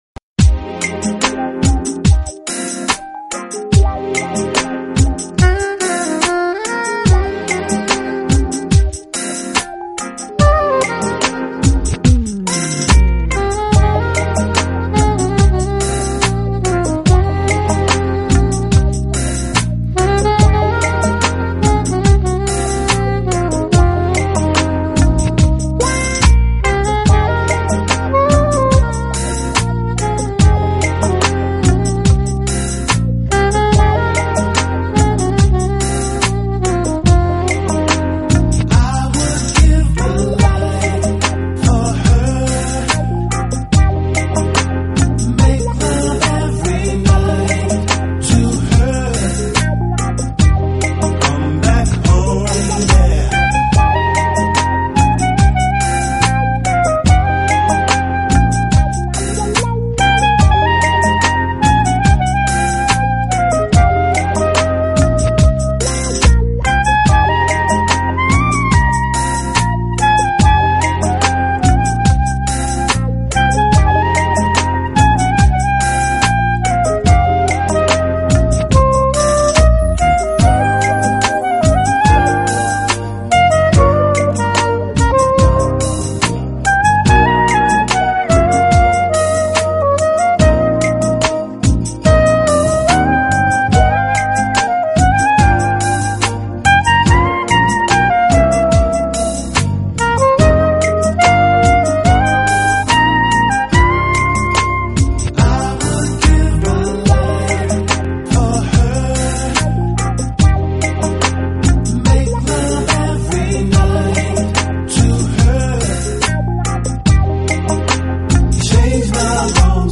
【爵士萨克斯】
音乐风格：Jazz, Smooth Jazz